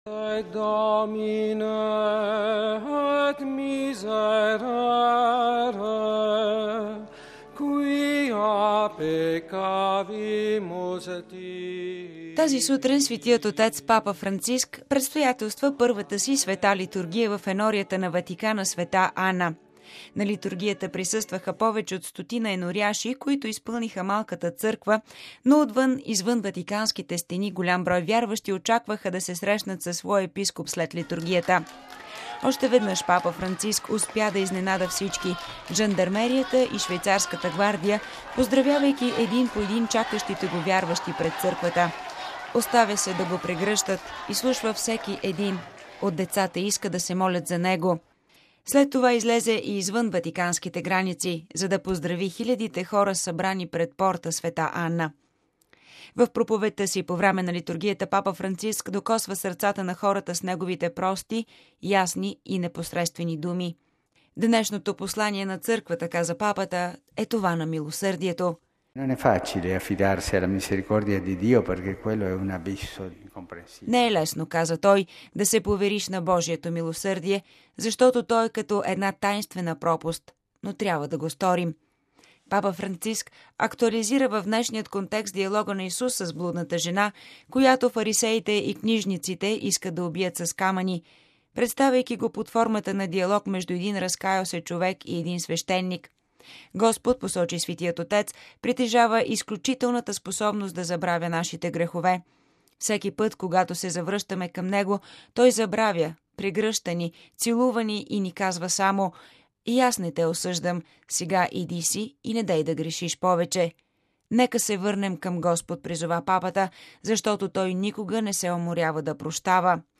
В проповедта си по време на литургията Папа Франциск докосва сърцата на хората с неговите прости, ясни и непосредствени думи.